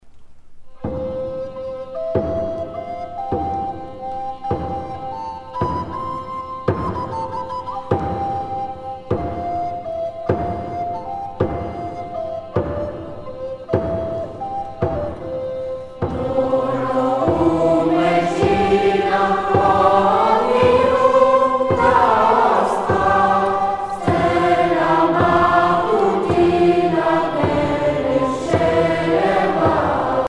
virelai
Pièce musicale éditée